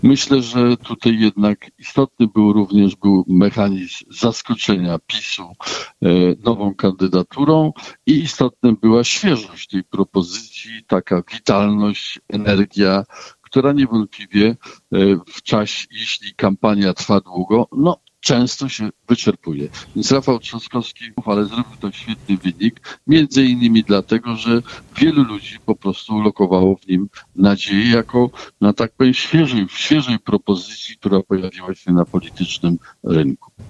Były prezydent RP był w poniedziałek (13.07) o poranku gościem Radia 5. Komentował wyniki drugiej tury wyborów prezydenckich.